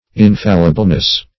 infallibleness - definition of infallibleness - synonyms, pronunciation, spelling from Free Dictionary
Search Result for " infallibleness" : The Collaborative International Dictionary of English v.0.48: Infallibleness \In*fal"li*ble*ness\, n. The state or quality of being infallible; infallibility.